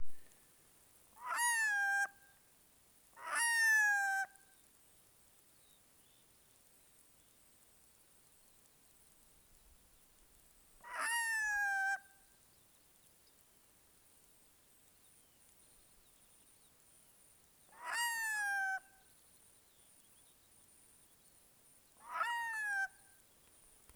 アビ
【分類】 アビ目 アビ科 アビ属 アビ 【分布】北海道(冬鳥)、本州(冬鳥)、四国(冬鳥)、九州(冬鳥)、沖縄(希な旅鳥または冬鳥) 【生息環境】沿岸、湾内、河口、海岸近くの湖沼に生息 【全長】63cm 【主な食べ物】魚 【鳴き声】地鳴き 【聞きなし】「アァーッ」「ツァーン」